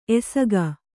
♪ esaga